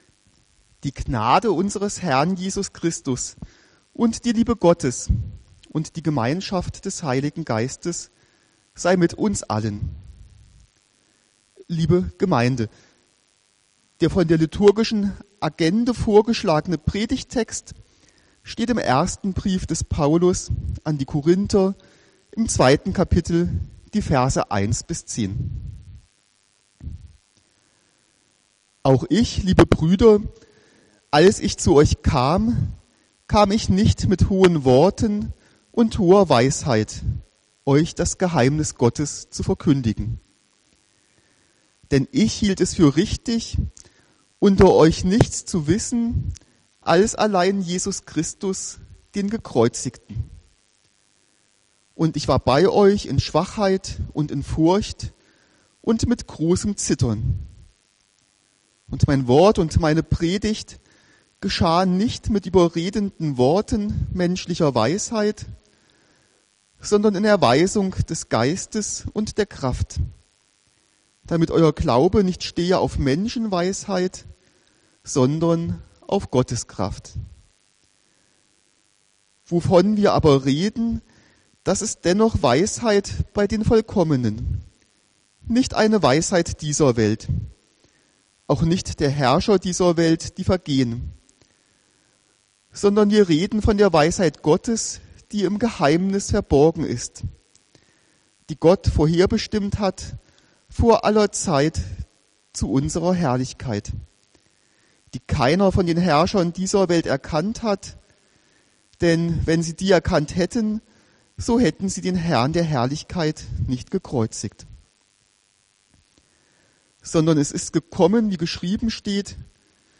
16.01.2022 Predigt